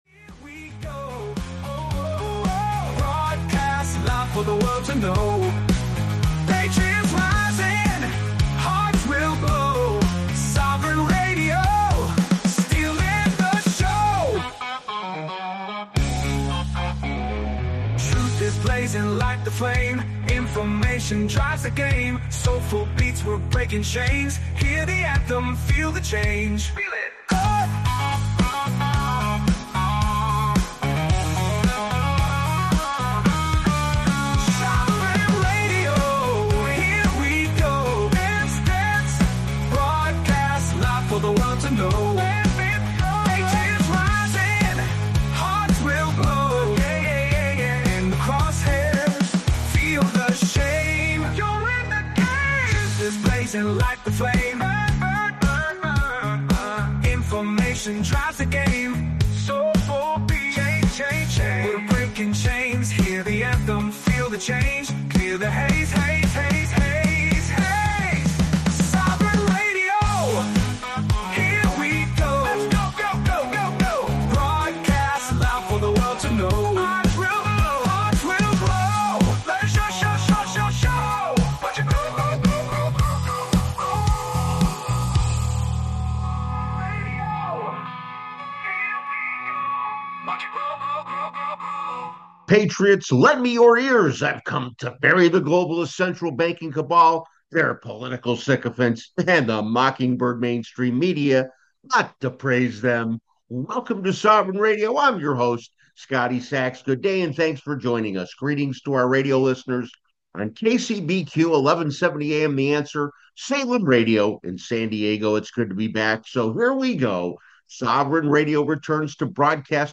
Tune in on KCBQ 1170am or online to learn more about protecting your future.